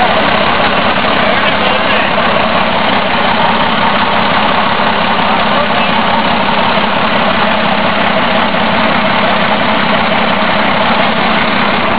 Ja ja, efter lite mera mecka så starta den.
motor.WAV